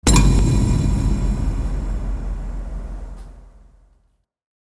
دانلود آهنگ تصادف 9 از افکت صوتی حمل و نقل
دانلود صدای تصادف 9 از ساعد نیوز با لینک مستقیم و کیفیت بالا
جلوه های صوتی